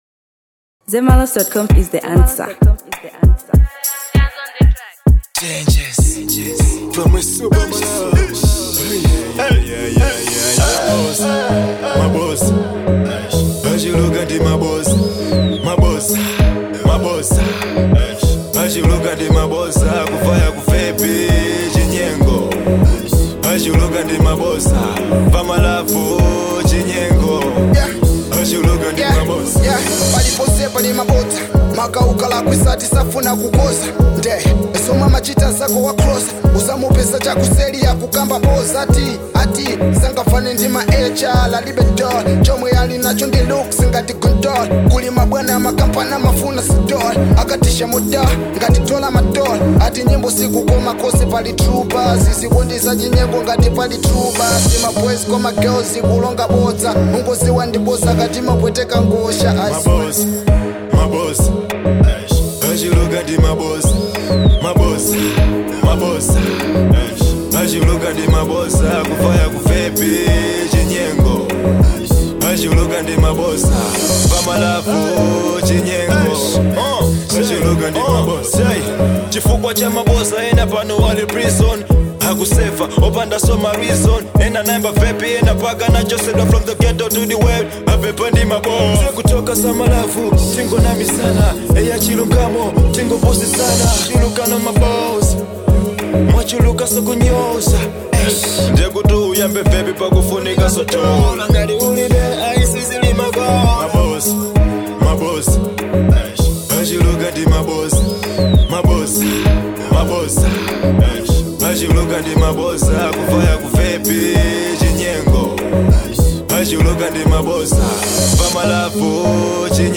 Kwaito|Afrobeats|Amapiano|Dancehall • 2025-07-12